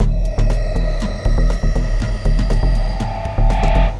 Action Stations (Full) 120BPM.wav